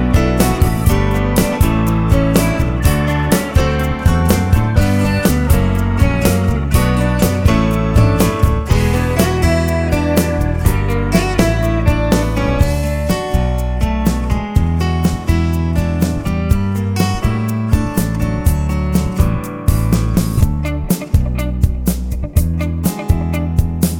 Two Semitones Down Jazz / Swing 3:31 Buy £1.50